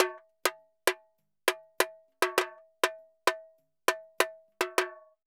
Tamborin Candombe 100_1.wav